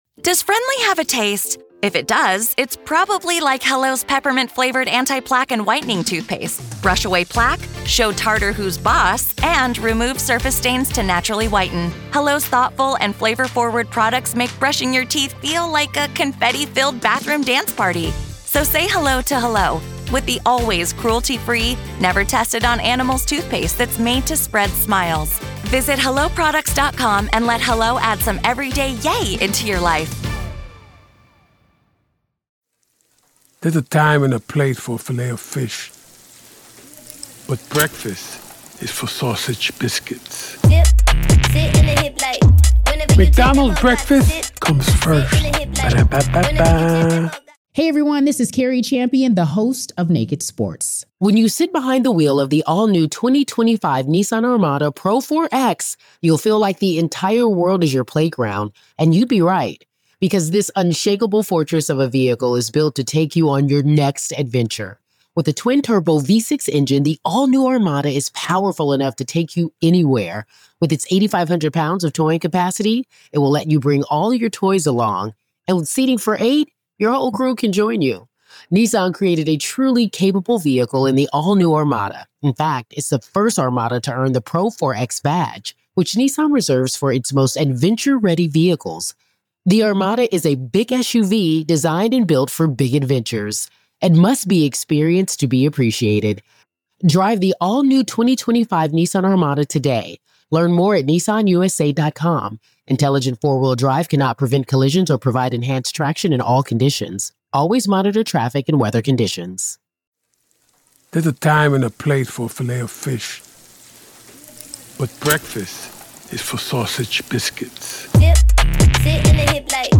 And through it all, you’ll hear Lori herself—calm, controlled, representing herself, cross-examining her own family, and showing little to no emotion as her beliefs, her behavior, and her potential motives are laid bare. This is the complete courtroom audio from April 10, 2025 —no edits, no filters, just the raw reality of one of the most bizarre and disturbing murder trials in modern history.